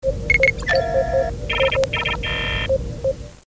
beeps-and-clicks.wav